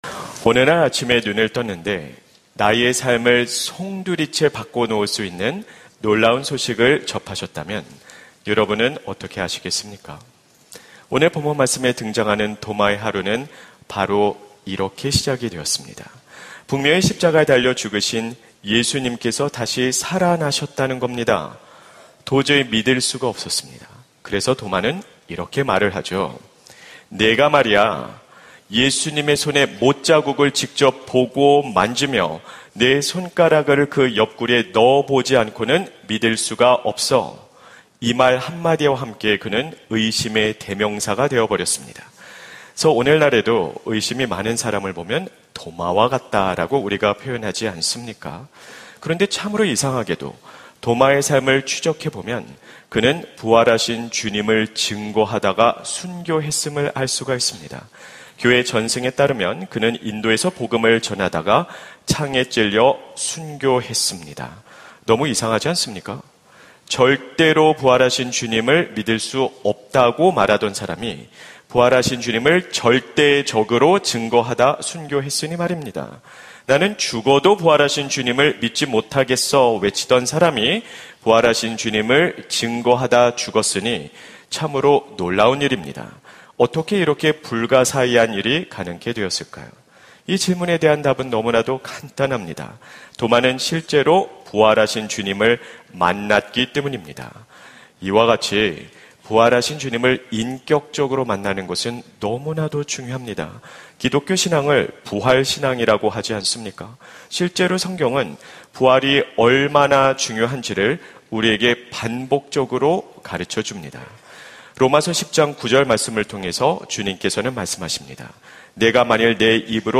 설교 : 주일예배 복음수업 - 복음수업 7 : 부활이 오늘 나와 무슨 상관이 있는가?